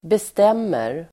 Uttal: [best'em:er]